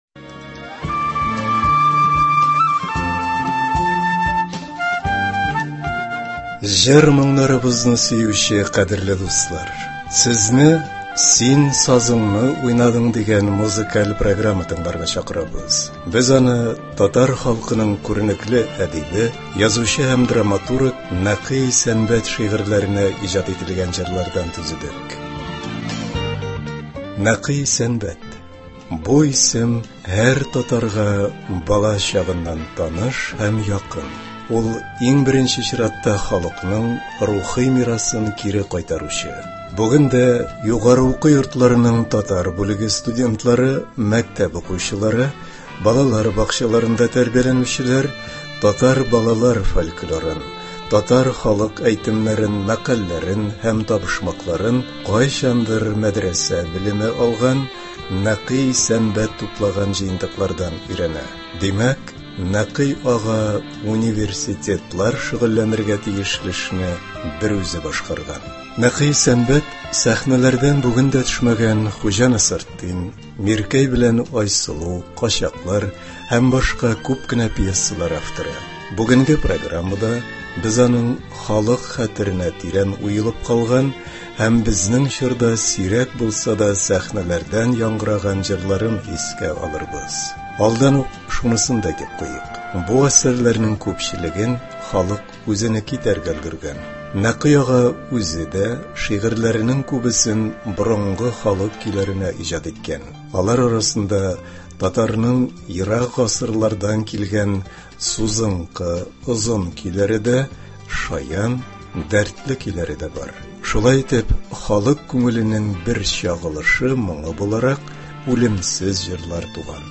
Кичке концерт. Нәкый Исәнбәт шигырьләреннән әдәби-музыкаль композиция.